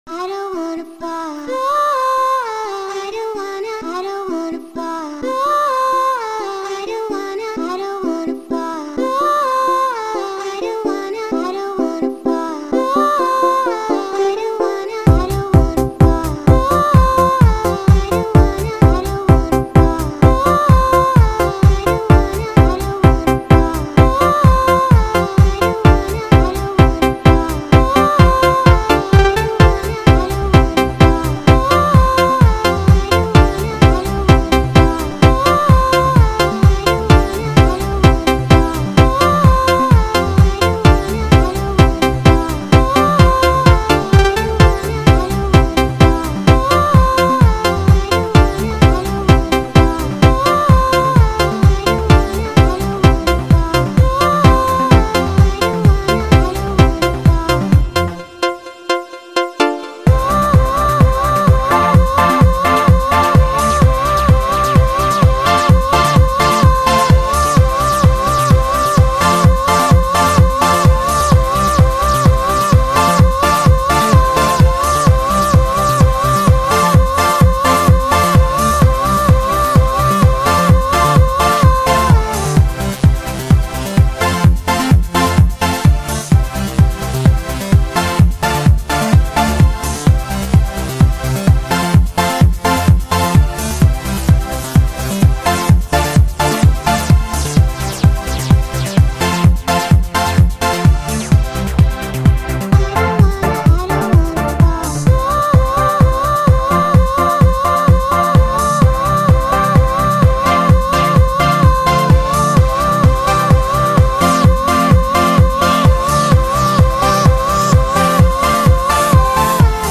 the little loop which eventually morphed into this track
awesome female backing vocals